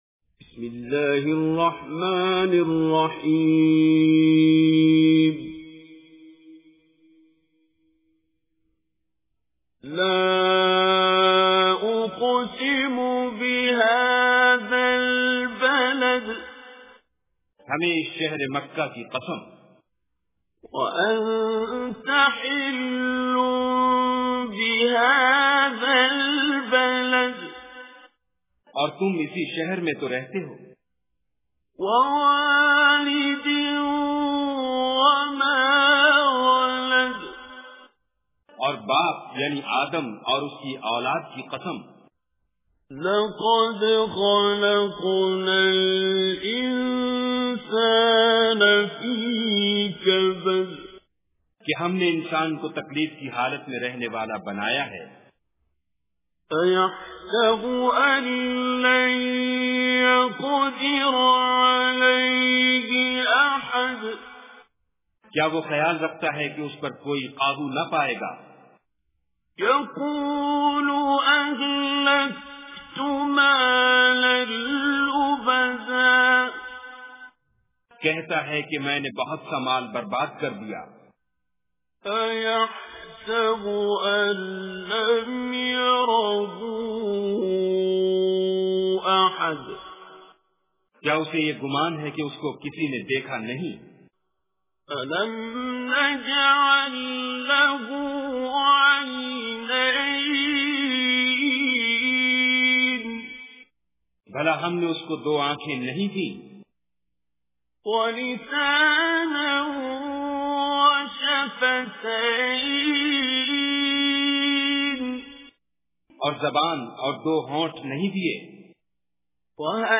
Surah Balad Recitation with Urdu Translation
Surah Balad 90 Surah of Holy Quran. Listen online and download mp3 tilaawat / recitation of Surah Balad in the voice of Qari Abdul Basit As Samad.